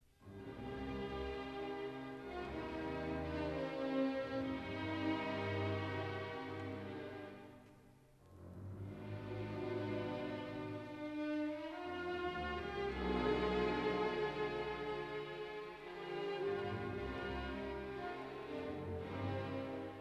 Interpret: symf. orchestr